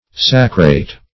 Search Result for " sacrate" : The Collaborative International Dictionary of English v.0.48: Sacrate \Sa"crate\, v. t. [L. sacratus, p. p. of sacrare.